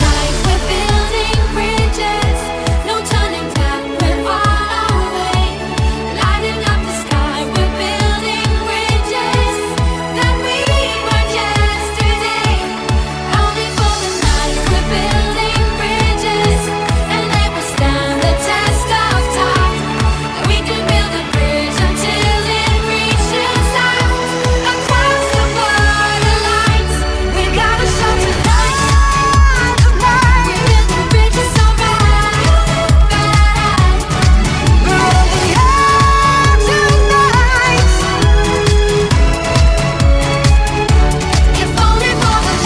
Genere: pop, dance